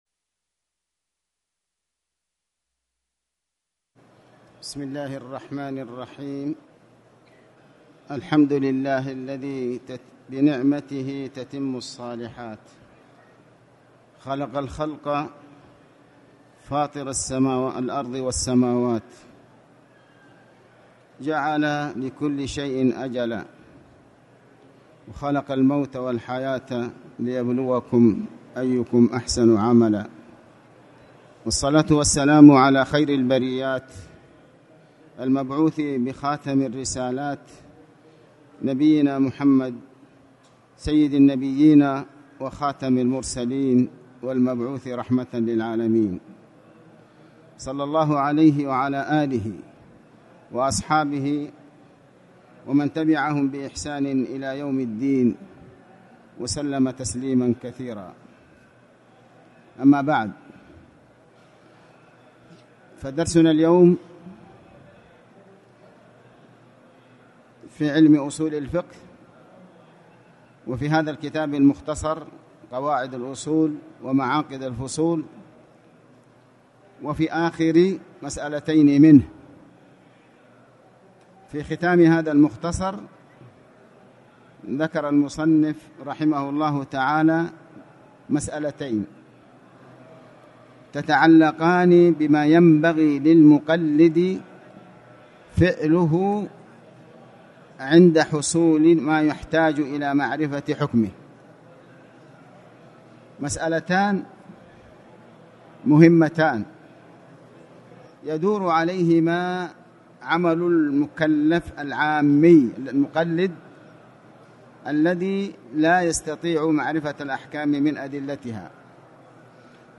تاريخ النشر ١٧ ذو القعدة ١٤٣٨ هـ المكان: المسجد الحرام الشيخ: علي بن عباس الحكمي علي بن عباس الحكمي ماينبغي للنقلد عند حصول مايحتاج معرفة حكمه